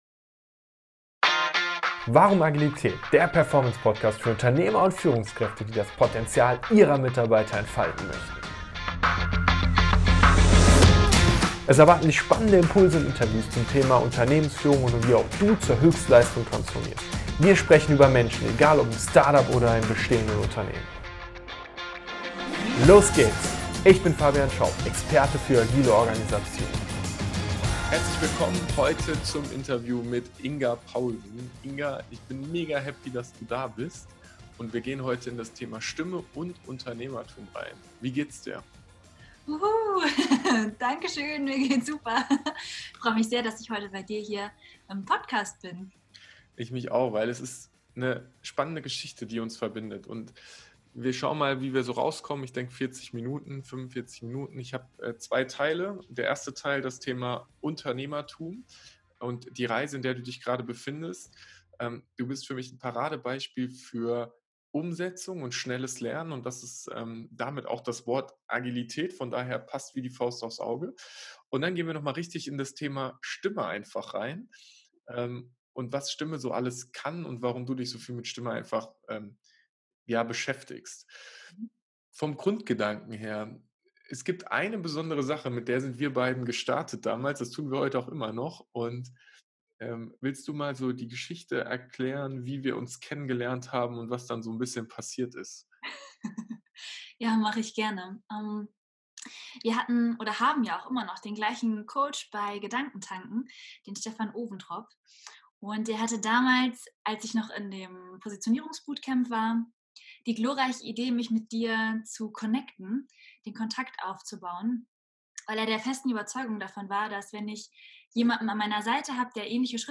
In unserem Interview sprechen wir über Unternehmertum und Stimme. Beides hängt enger zusammen, als ich es zuerst vermutet habe.